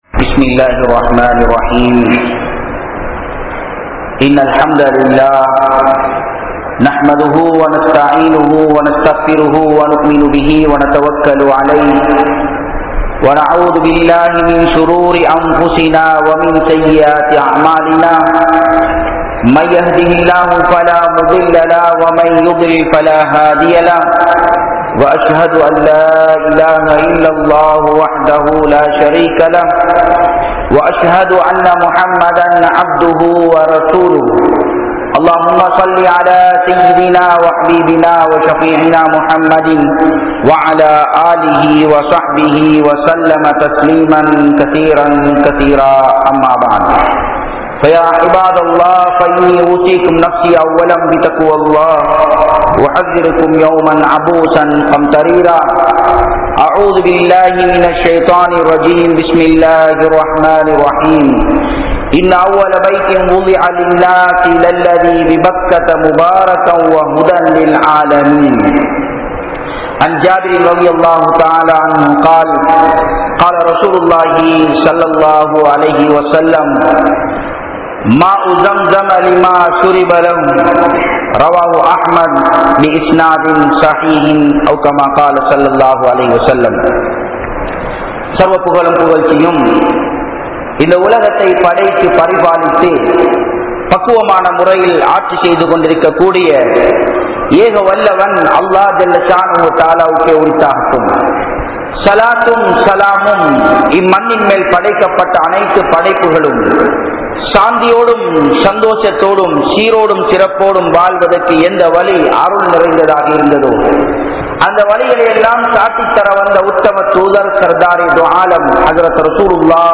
Atputhamaana Zam Zam (அற்புதமான ஸம் ஸம்) | Audio Bayans | All Ceylon Muslim Youth Community | Addalaichenai
Matala, Warakamura Jumua Masjidh